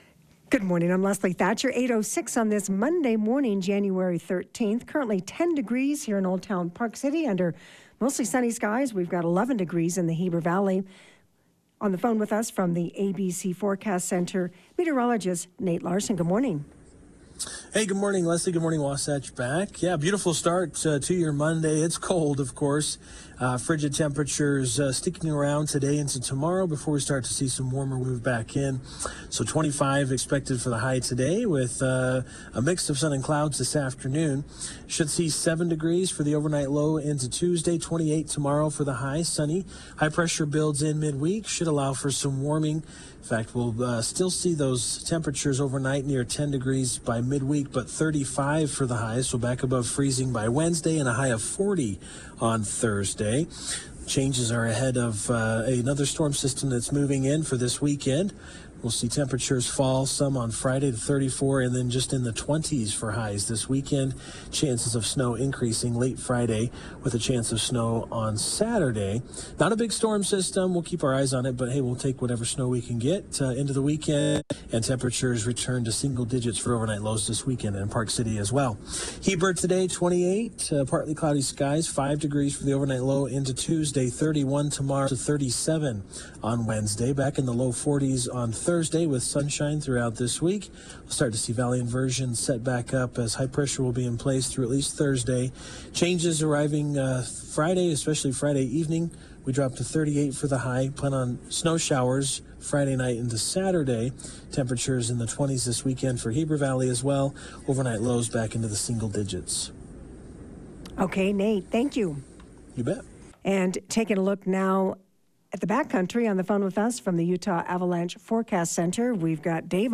Get the information directly from the people making news in the Wasatch Back with live interviews every weekday.